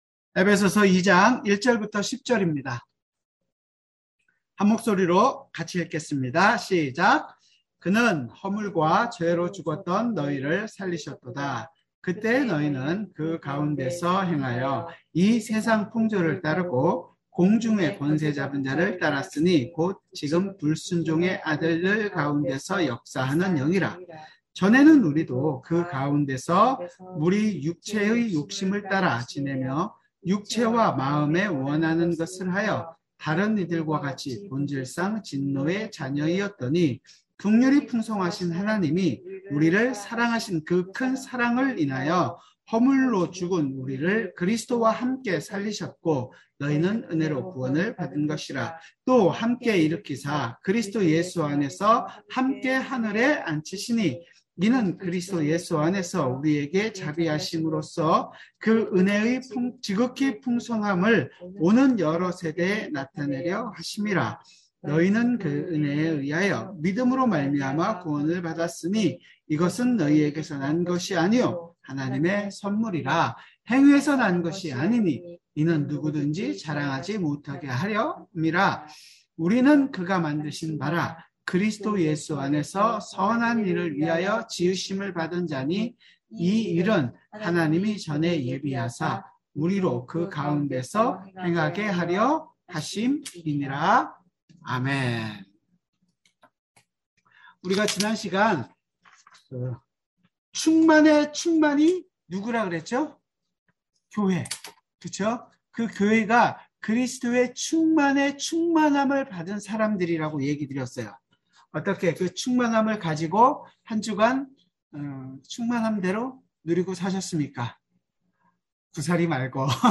수요성경공부